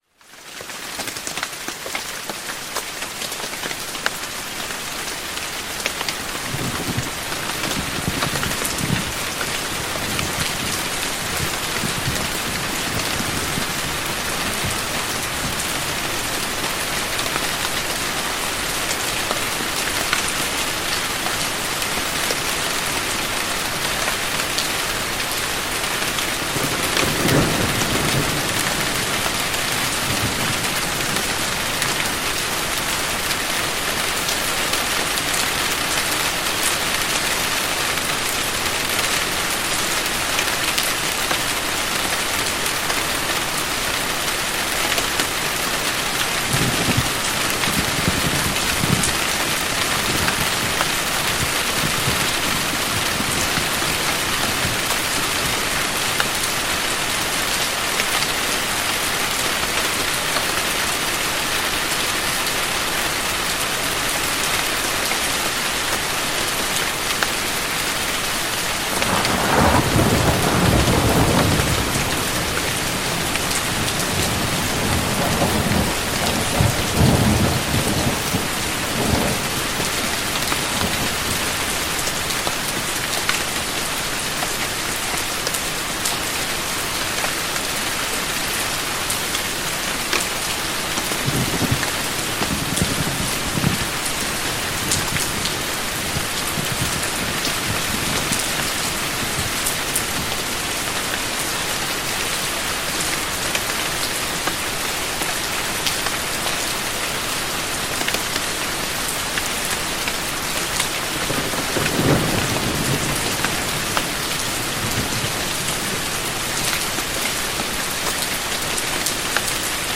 (Ads may play before the episode begins.)The rain begins softly, a gentle rhythm across the roof, then deepens into a powerful harmony of thunder and calm.